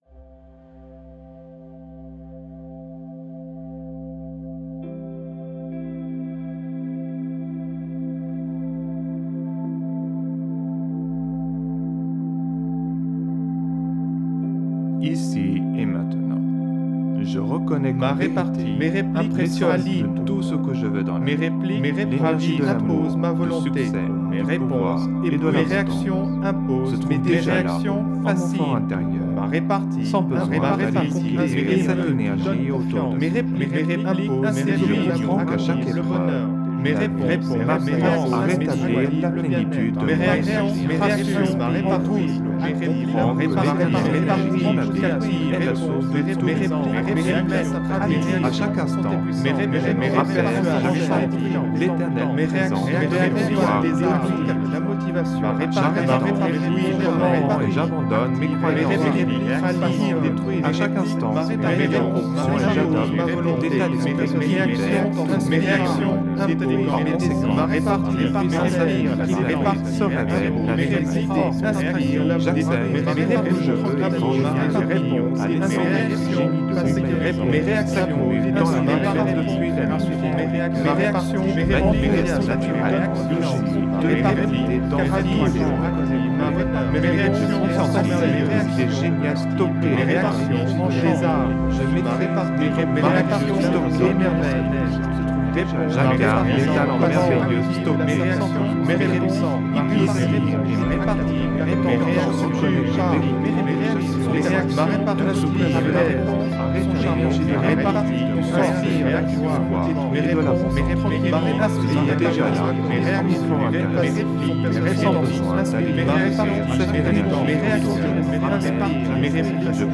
(Version INTENSE)
LA QUINTESSENCE « tout-en-un » du développement personnel concentrée en un seul produit : Méditation, autosuggestion, message subliminal, musicothérapie, son binaural, fréquences sacrées, son isochrone, auto hypnose, introspection, programmation neurolinguistique, philosophie, spiritualité, musique subliminale et psychologie.
Alliage ingénieux de sons et fréquences curatives, très bénéfiques pour le cerveau.
Puissant effet 360° subliminal.